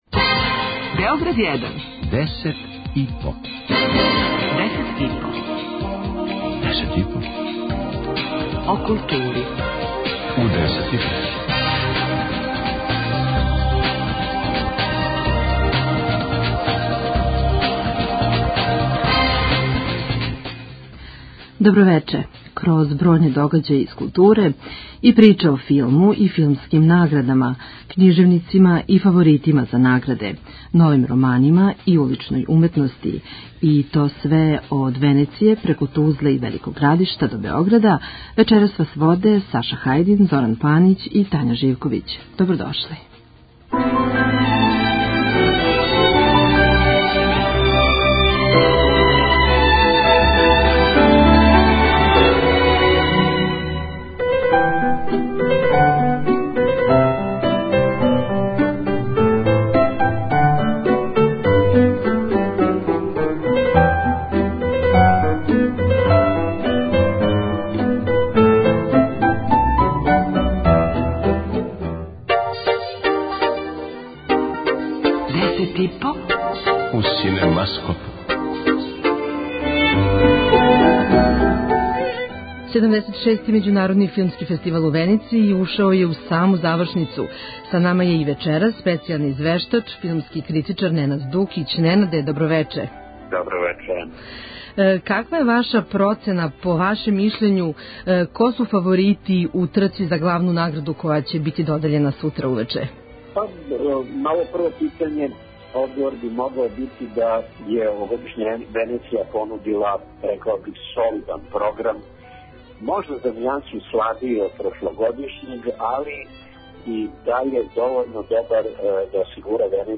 преузми : 3.59 MB Десет и по Autor: Тим аутора Дневни информативни магазин из културе и уметности. Вести, извештаји, гости, представљање нових књига, концерата, фестивала, репортерска јављања са изложби, позоришних и филмских премијера и најактуелнијих културних догађаја.